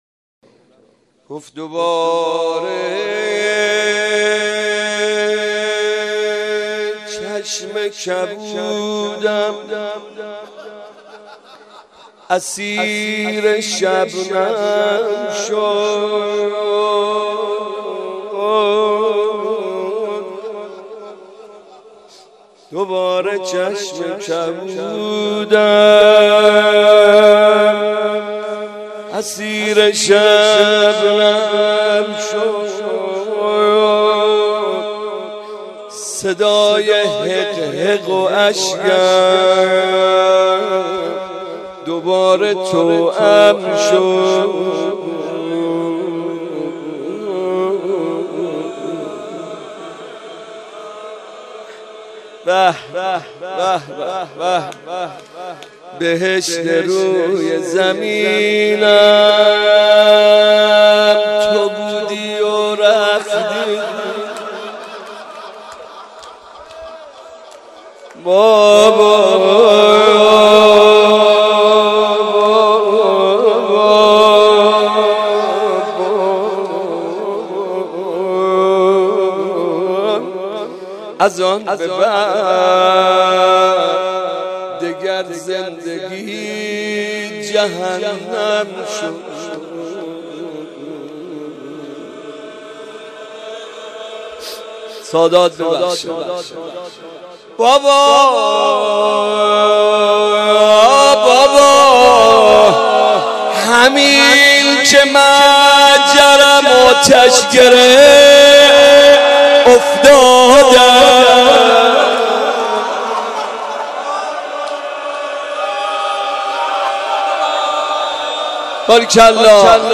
مراسم شب سوم ماه مبارک رمضان